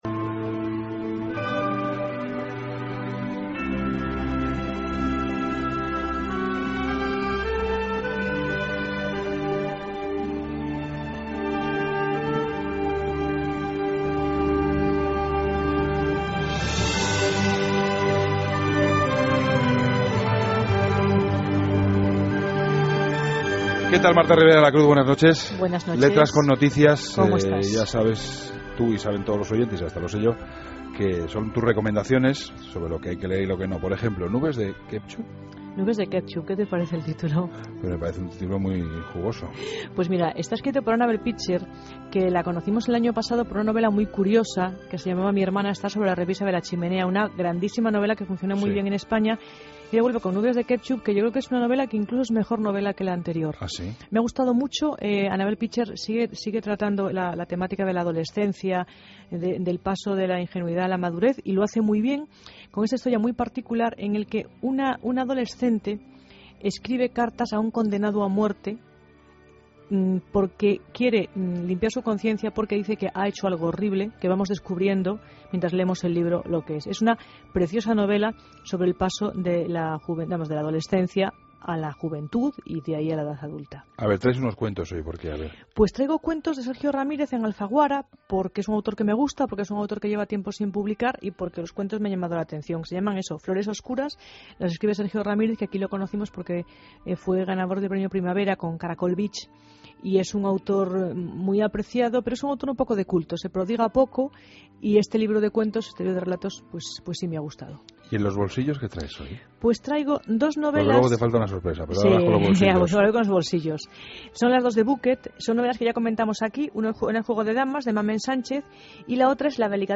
Entrevistra a MArtín CAsariego, autor de 'Un amigo así'